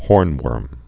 (hôrnwûrm)